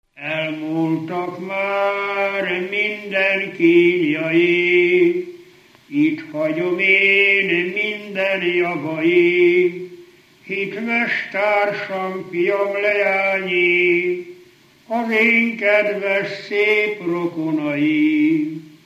Alföld - Bács-Bodrog vm. - Mélykút
ének
Stílus: 8. Újszerű kisambitusú dallamok
Kadencia: 2 (2) 3 1